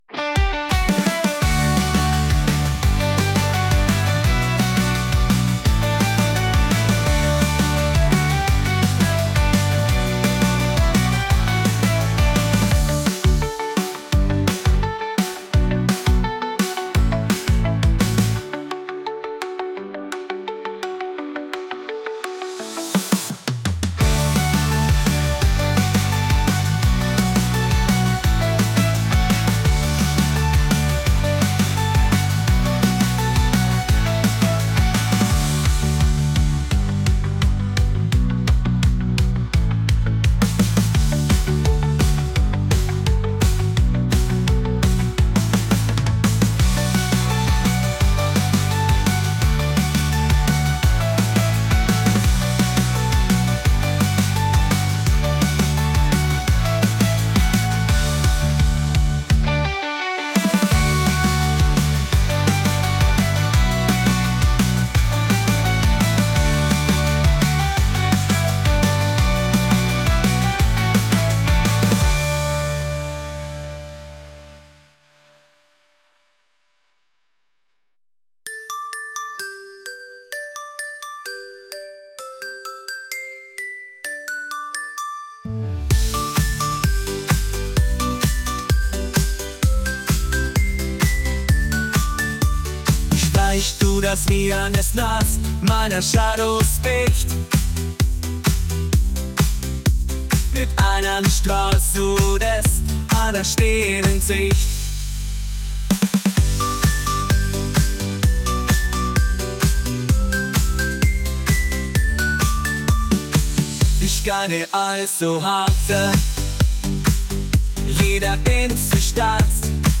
pop | upbeat | catchy